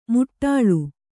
♪ muṭṭāḷu